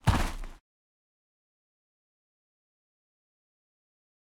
PixelPerfectionCE/assets/minecraft/sounds/mob/guardian/land_idle1.ogg at mc116
land_idle1.ogg